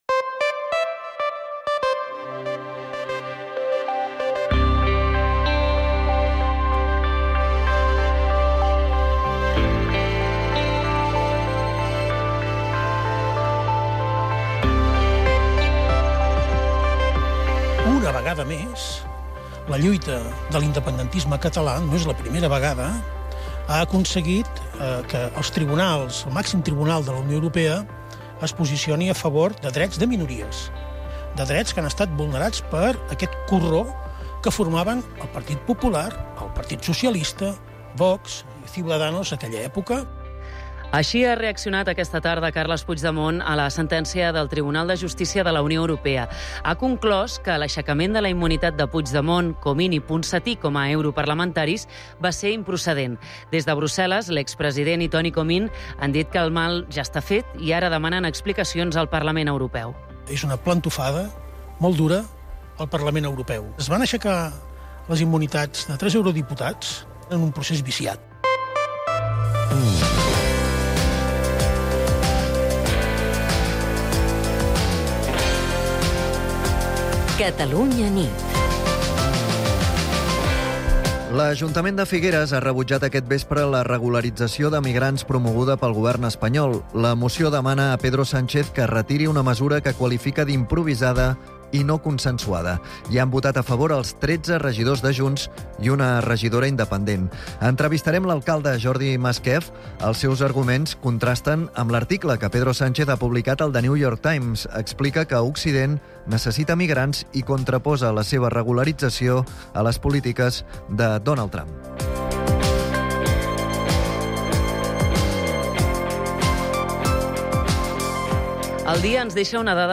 Connectem amb Brussel·les el dia que la justícia europea ha donat finalment la raó a Carles Puigdemont, Toni Comín i Clara Ponsatí en el cas sobre la seva immunitat com a europarlamentaris. També conversem amb l'alcalde de Figueres, Jordi Masquef, després que el ple del municipi ha aprovat aquest vespre la moció contra la regularització d'immigrants anunciada pel govern espanyol.